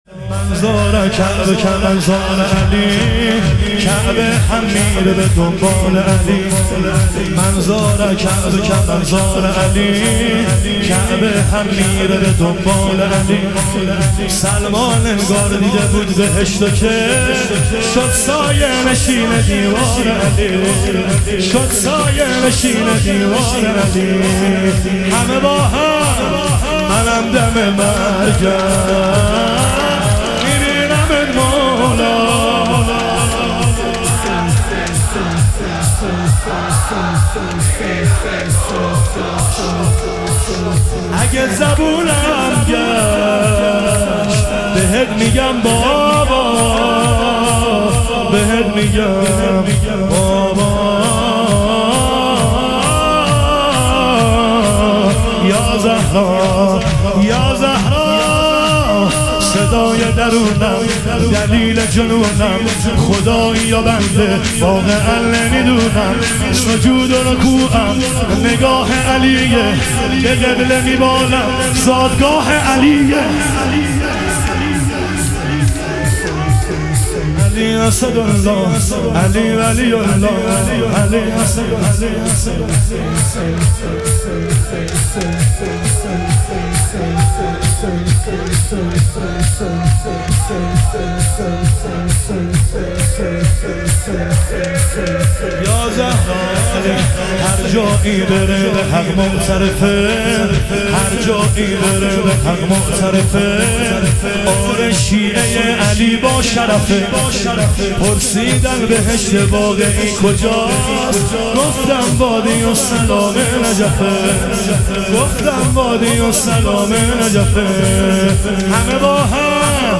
شهادت حضرت خدیجه علیها سلام - شور